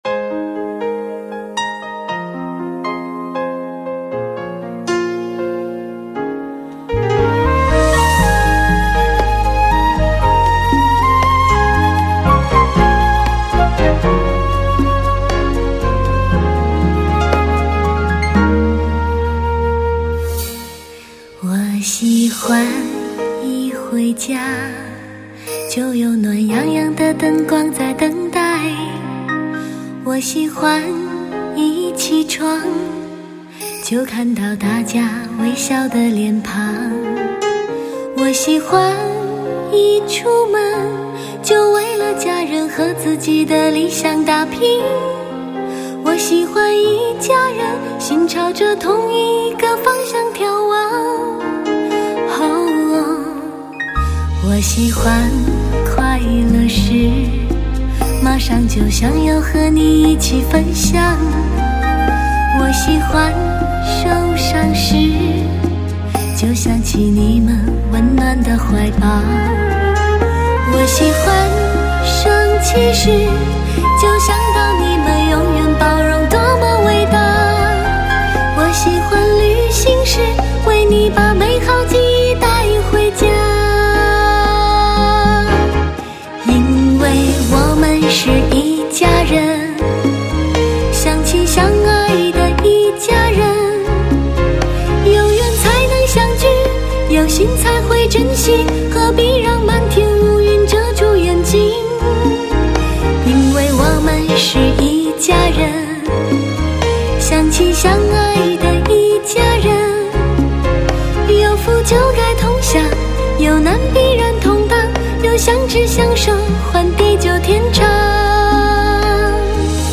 DTS-ES6.1
甜声动人直入心扉 动情唱响